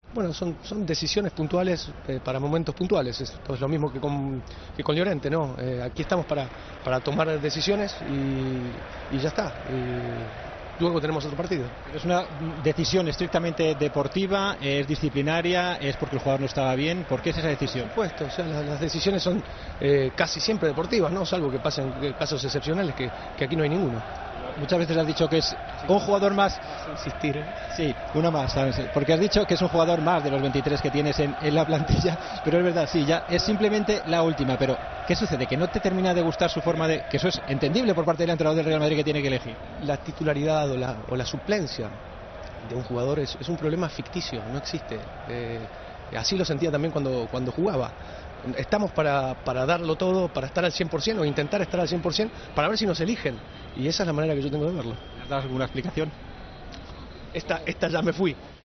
Santiago Solari explicó en Movistar por qué no convocó a Isco ante la Roma: "Son decisiones puntuales para momentos puntuales".